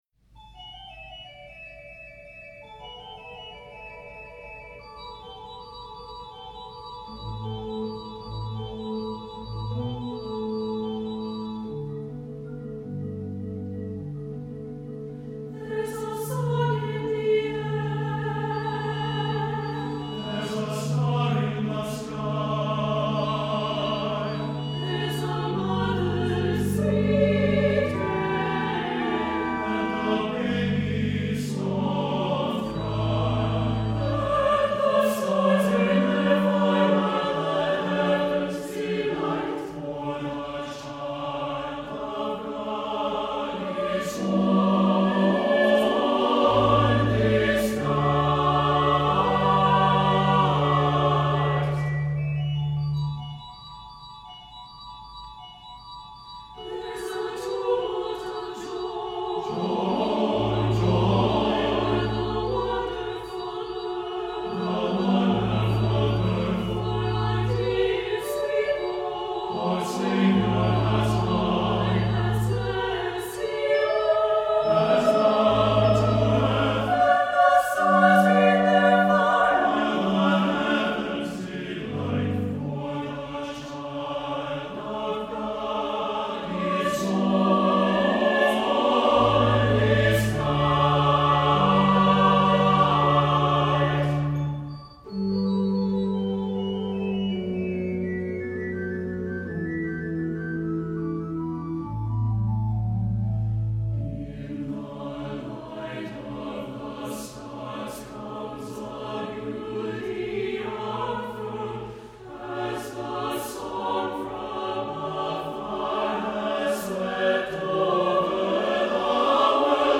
for SATB Chorus and Organ (2013)